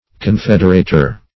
Confederater \Con*fed"er*a`ter\, n.